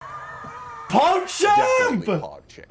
Play, download and share xqc pogchamp original sound button!!!!